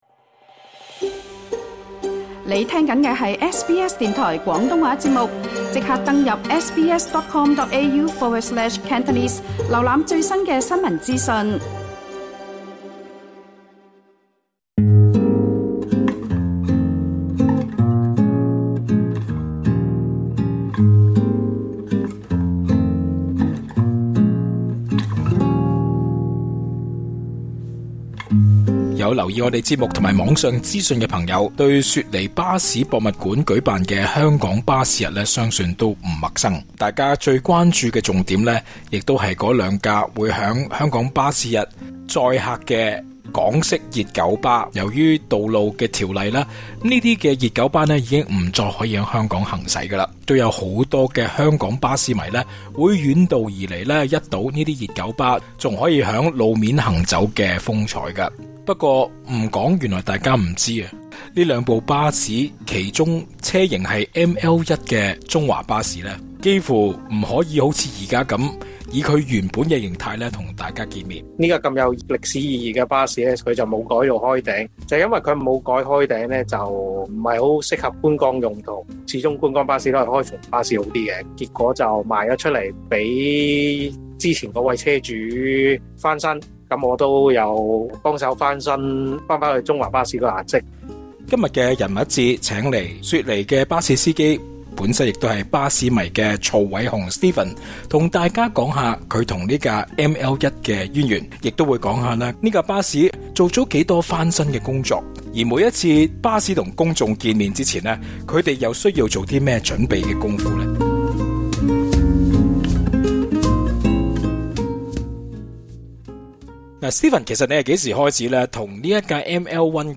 除了巴士日，大家還能在哪些日子，可以目暏這兩間「港產熱狗巴」的風采，相知就要留意足本錄音訪問。